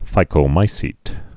(fīkō-mīsēt, -mī-sēt)